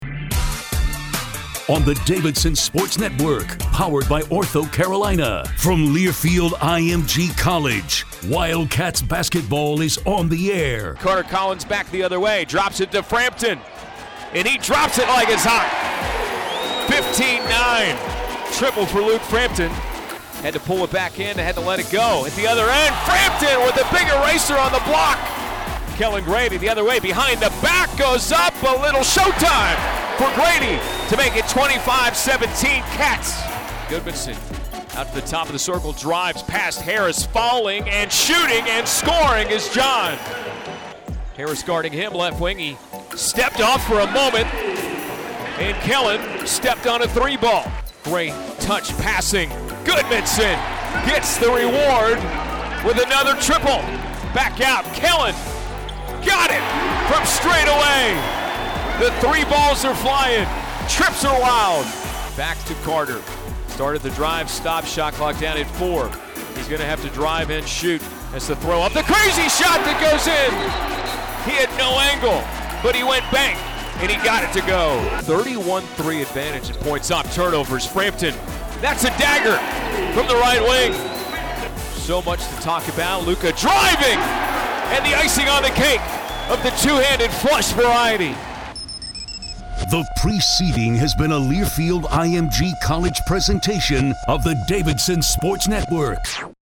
Radio Highlights
Nevada at Davidson Highlights.mp3